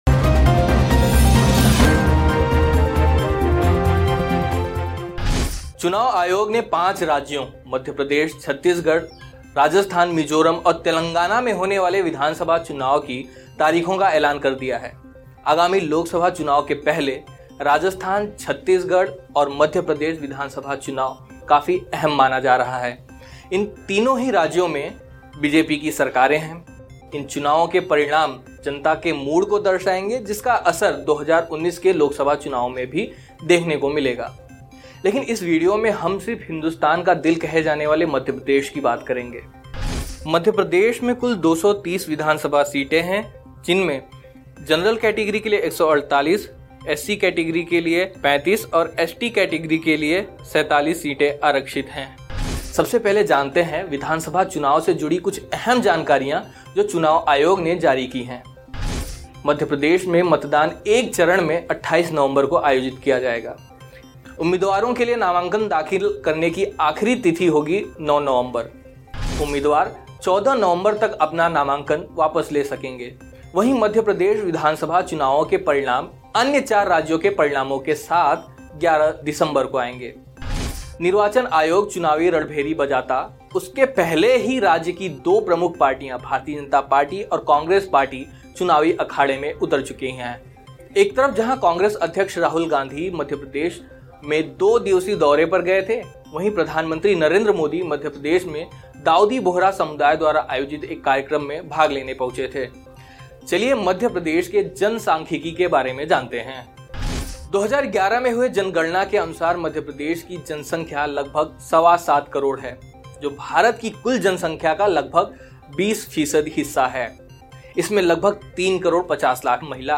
न्यूज़ रिपोर्ट - News Report Hindi / निर्वाचन आयोग ने एमपी विधानसभा चुनाव के लिए बजा दी रणभेरी, जानें कब क्या होगा?